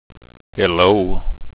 This page opens with a "Hello" sound in WAV format.
Hello.wav